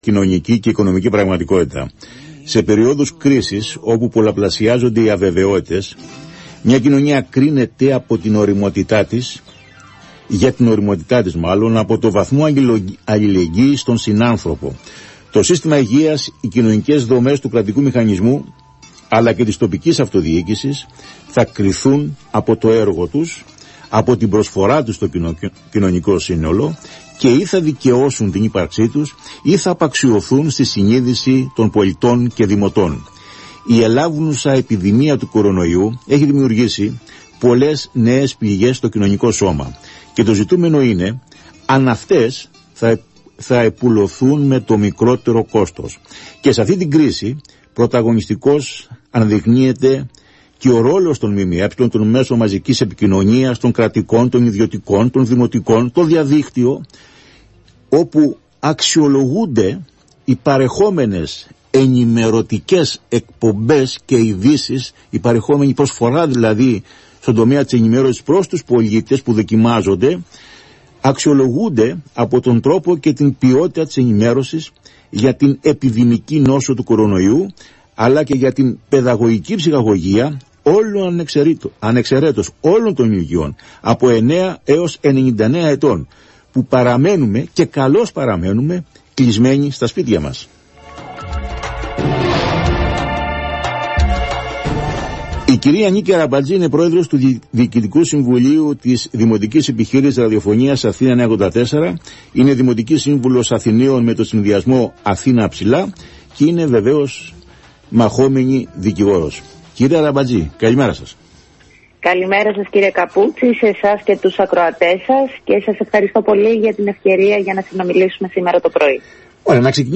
Για τα προτάγματα και τη συγκυρία της εποχής συζήτησε η πρόεδρος του Δ.Σ. της Δημοτικής Επιχείρησης Ραδιοφωνίας ΑΘΗΝΑ 9.84 και Δημοτική Σύμβουλος με το Συνδυασμό «Αθήνα Ψηλά» του Δημάρχου Αθηναίων, Νίκη Αραμπατζή